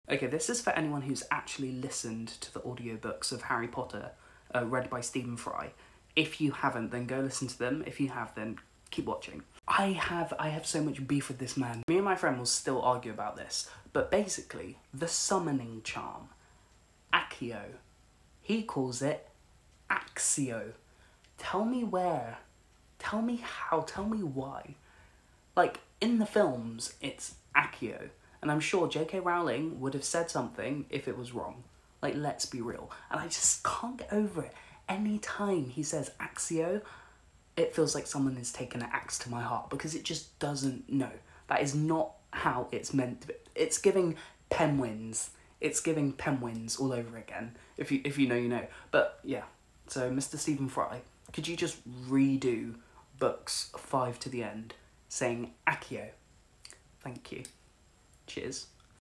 Okay I’m sure the Latin is pronounced Fry’s way but you can’t tell me that it isn’t AKKKKIO in the film. ACSSSSIO < AKKKKIO